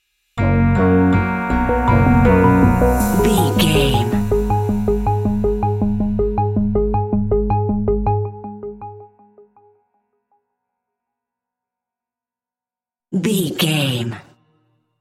Aeolian/Minor
G#
Slow
ominous
dark
eerie
piano
synthesiser
drums
instrumentals